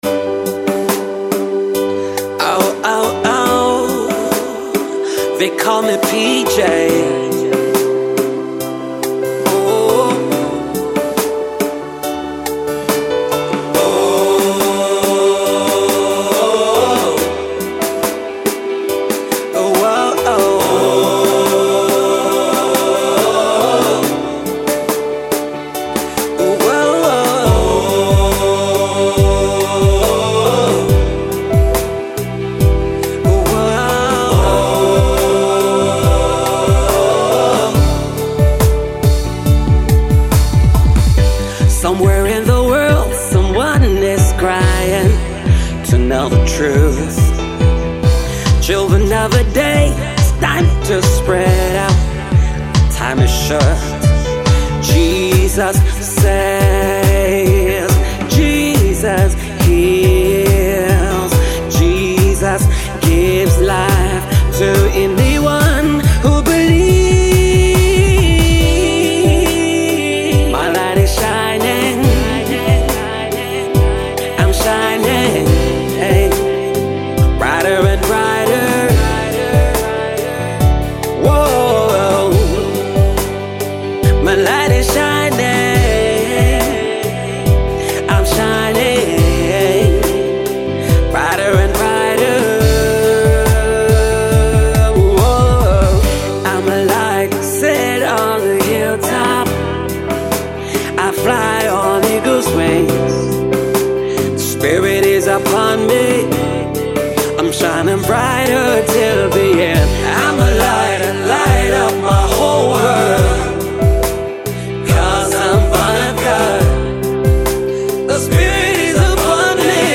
Contemporary Christian Music Artiste